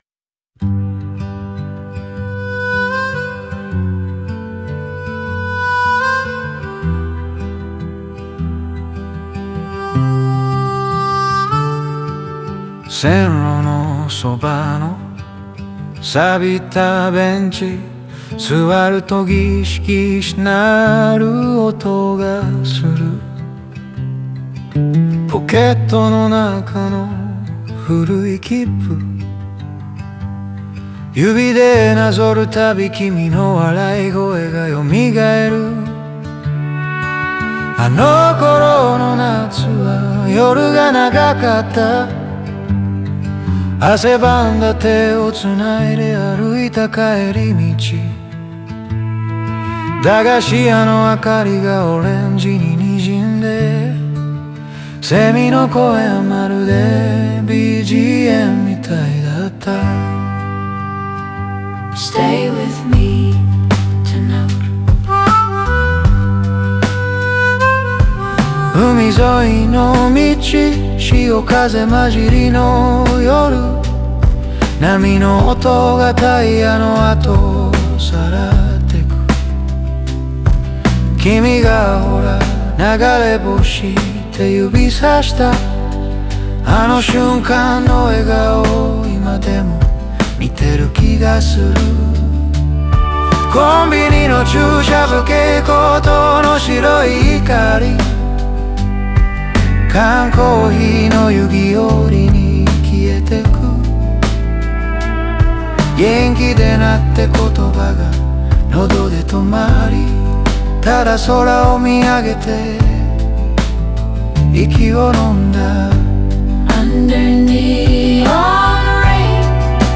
ハーモニカとアコースティックの音色が、静かな夜を切り裂くように心に響く叙情的なロードソング。